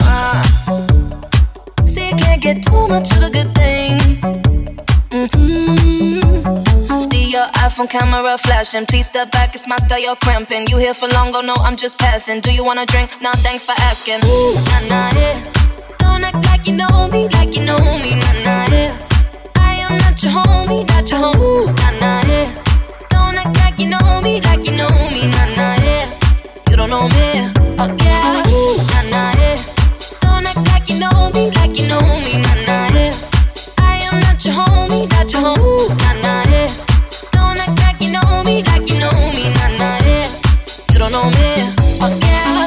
Genere: pop, club, deep, remix